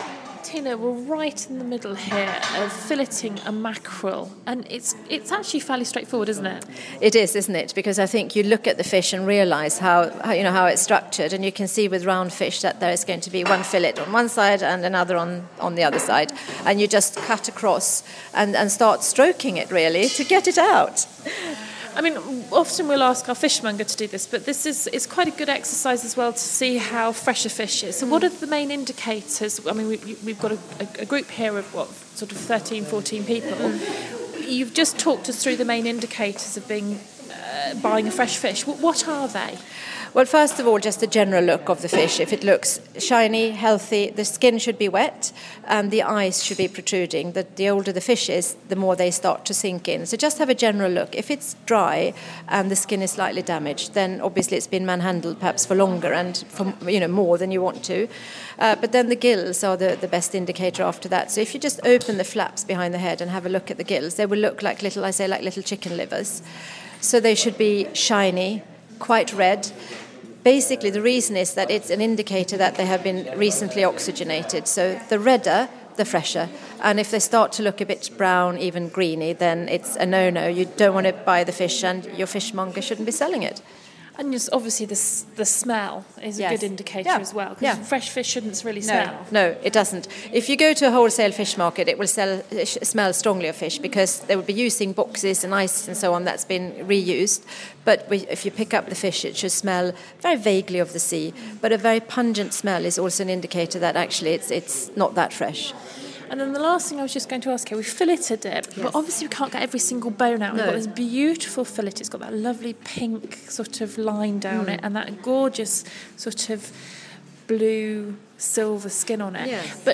at the Cambridge Cookery School